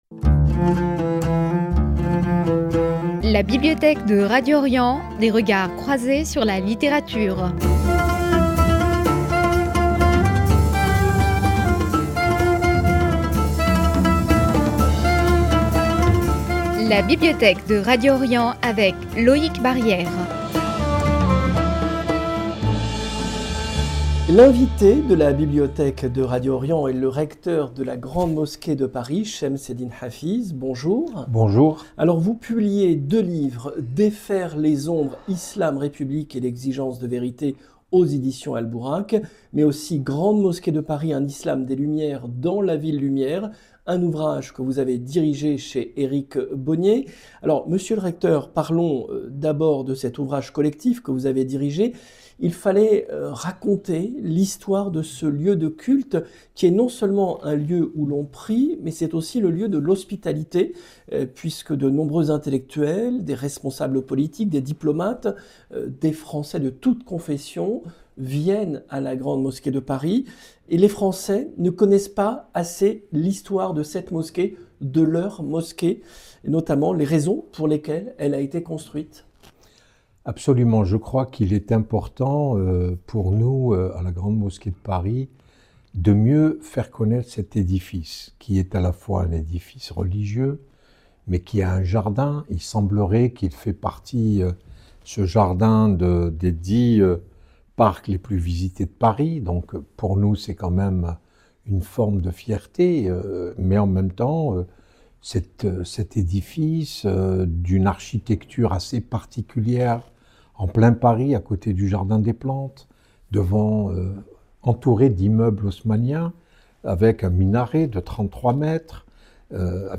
L’invité de la Bibliothèque de Radio Orient est le recteur de la Grande Mosquée de Paris, Chems-Eddine Hafiz , pour deux livres.